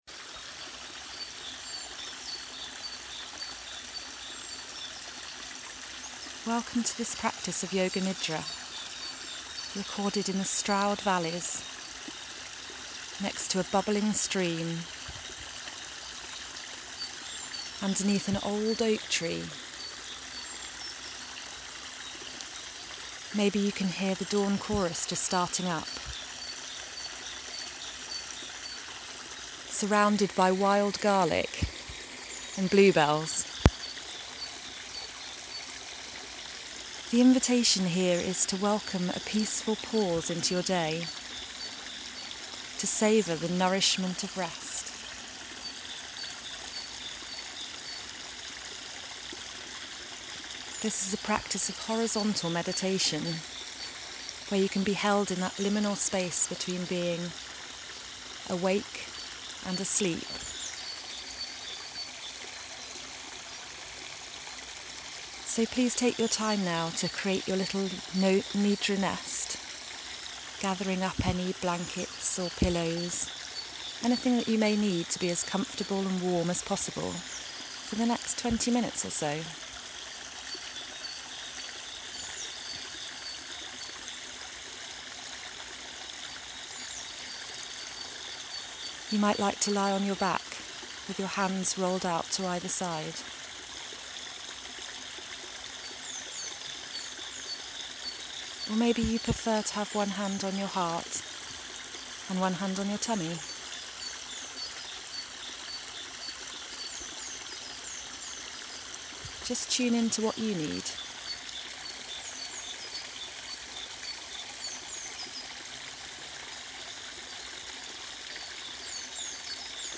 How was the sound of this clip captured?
A 20 minute peaceful pause nidra to savour the nourishment of rest, recorded in the Cotswolds by a stream surrounded by wild garlic and bluebells.